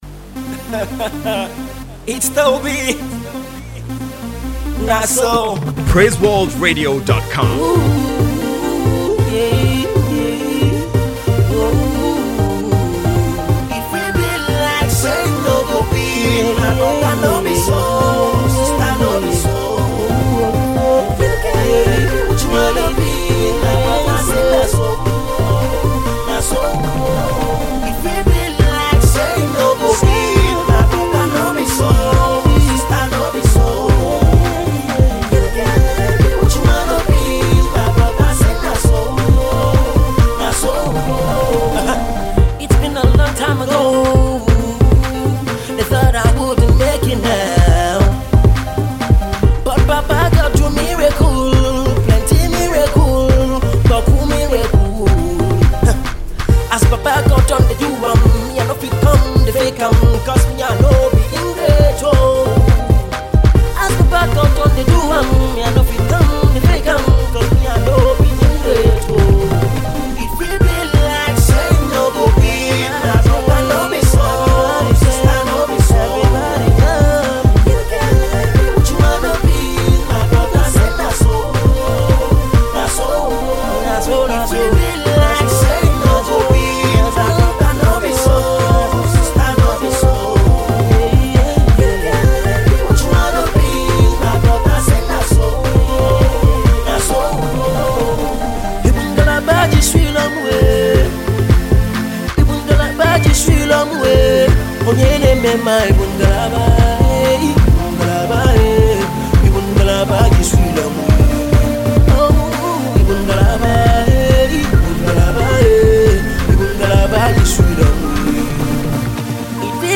Nigerian gospel artiste